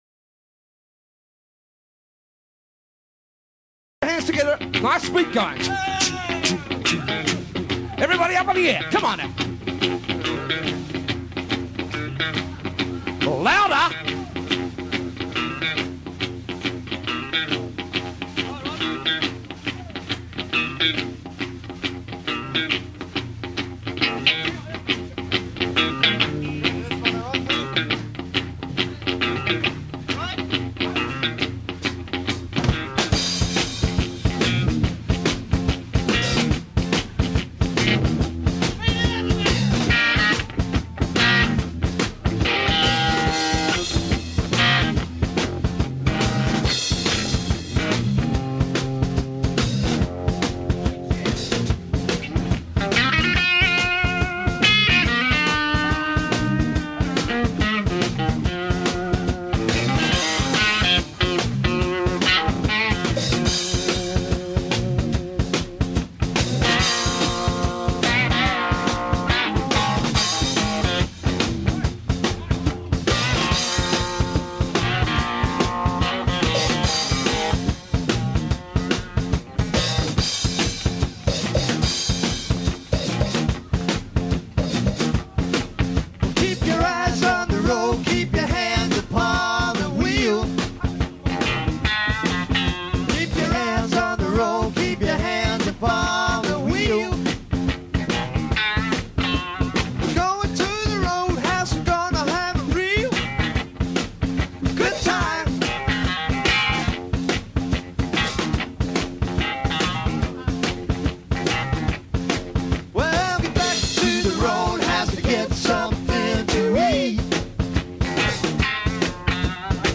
Free-for-all jam